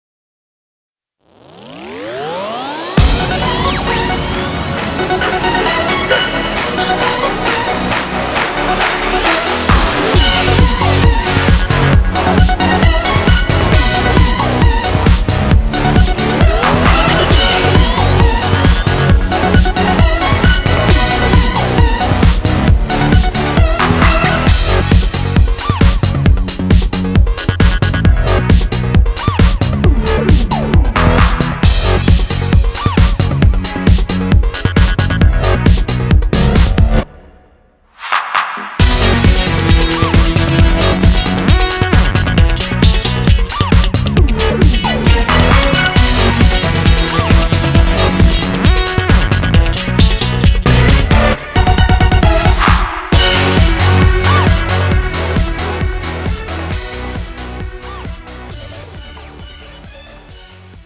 장르 가요 구분 Premium MR